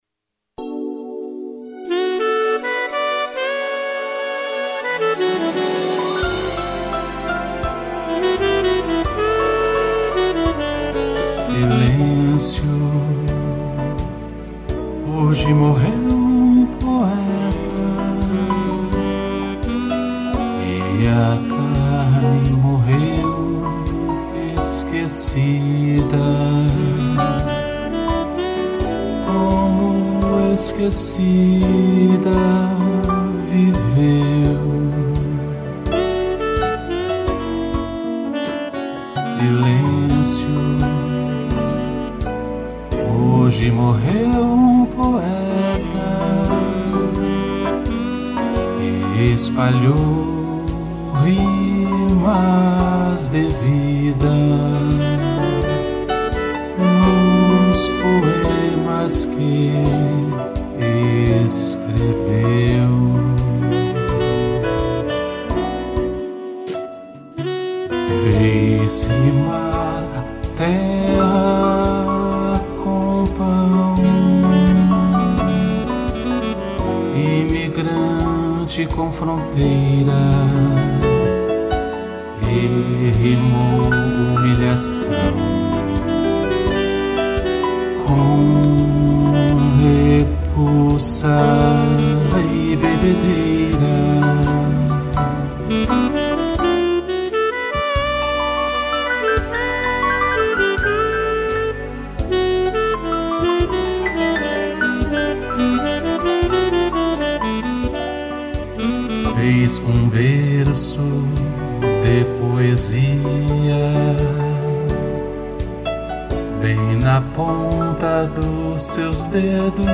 piano e sax